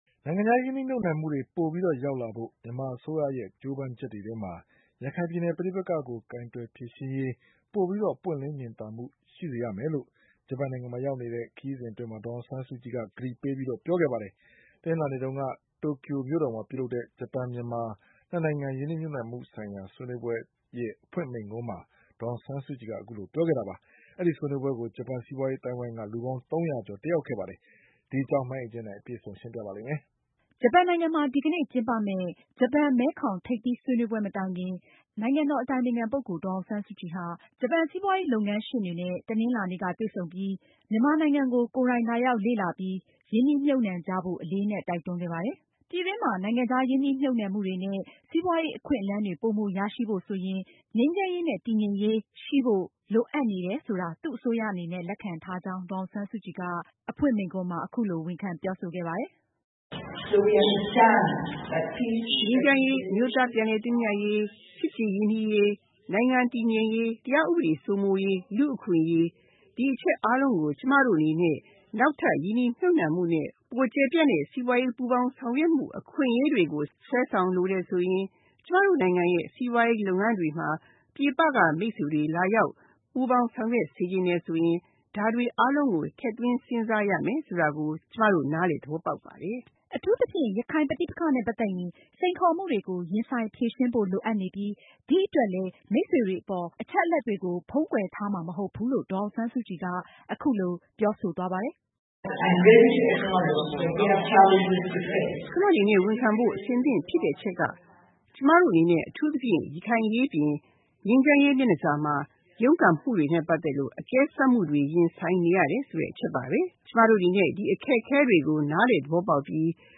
Daw Aung San Suu Kyi gave an opening speech at Japan-Myanmar Investment Conference in Tokyo